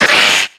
Cri de Carvanha dans Pokémon X et Y.